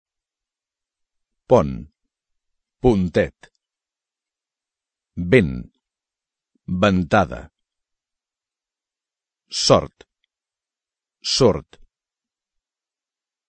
Fixa’t en les vocals destacades de les paraules següents i escolta’n la pronunciació:
La o de pont es pronuncia amb el so d’o (oberta) i, en canvi, aquesta mateixa o es pronuncia u en el diminutiu pontet (en algunes varietats del català).
El so de la e de ventada (vocal neutra) no és el mateix de la e de vent (e tancada).
La o de sort és oberta i, en canvi, la o de sord és tancada.